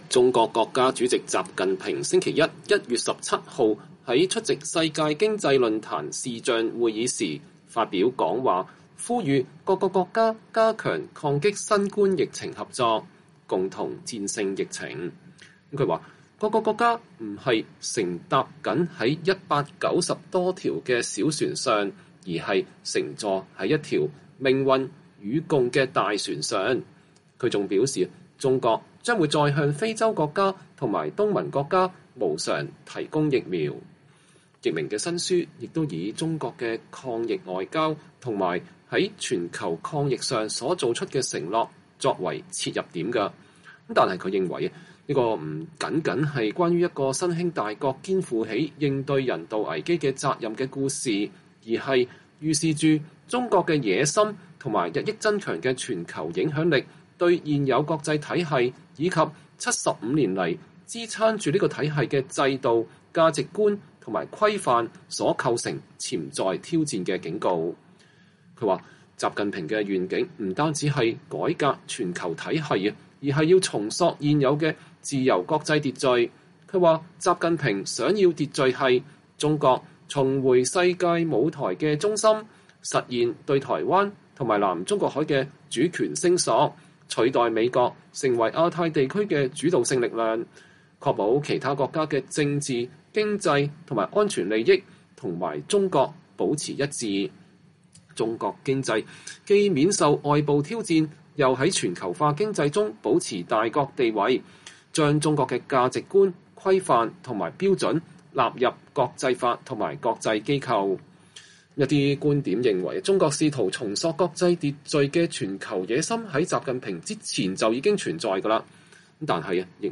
專訪中國問題專家易明：習近平想重塑世界？肯定的！